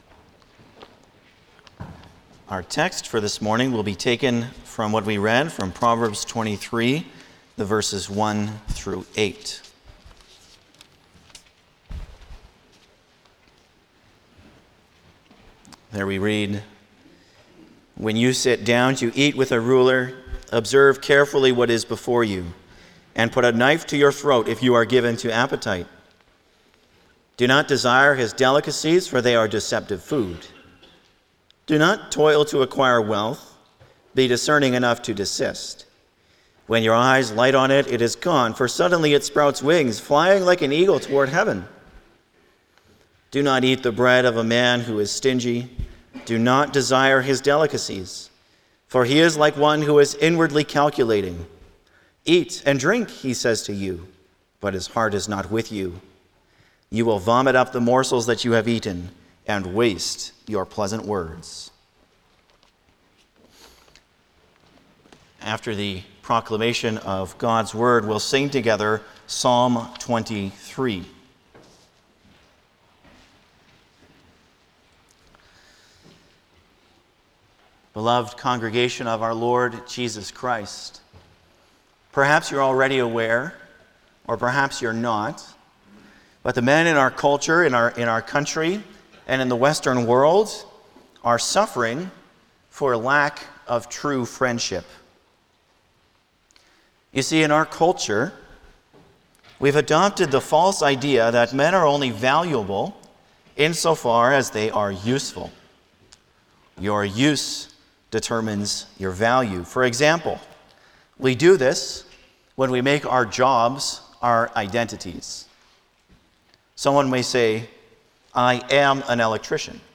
Service Type: Sunday morning
08-Sermon.mp3